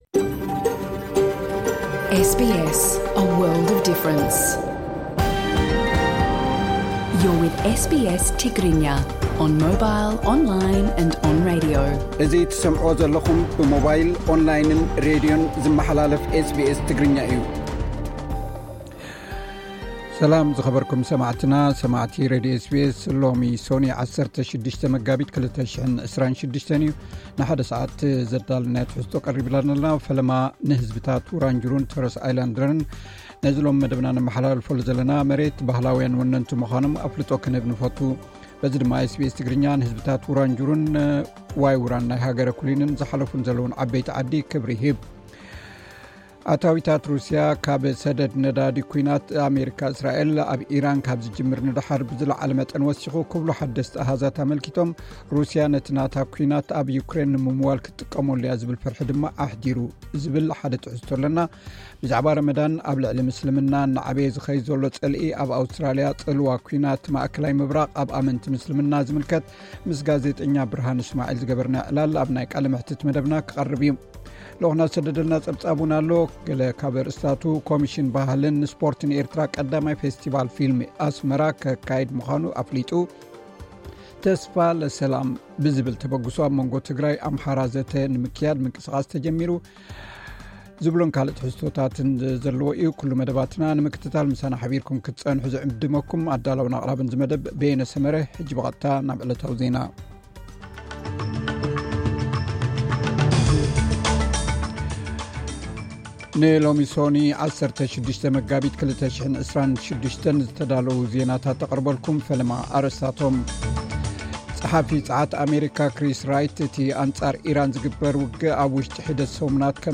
ቀጥታ ምሉእ ትሕዝቶ SBS ትግርኛ (16 መጋቢት 2026)